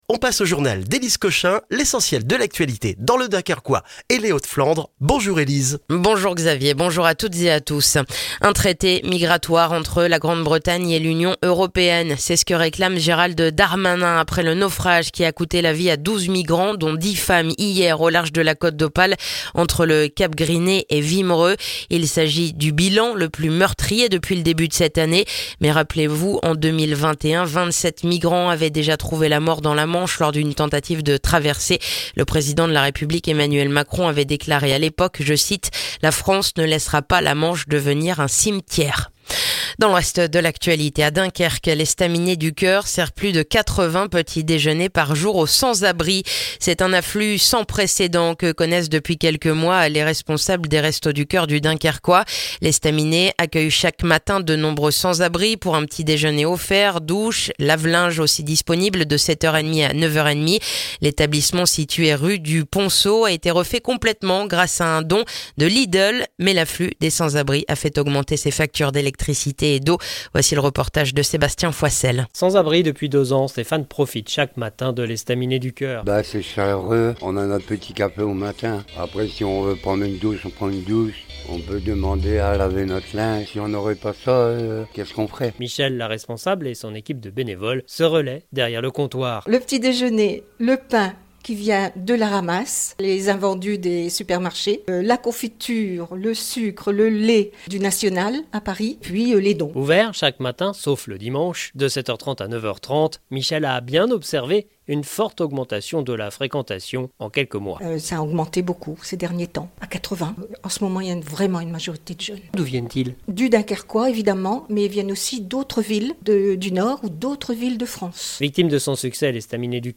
Le journal du mercredi 4 septembre dans le dunkerquois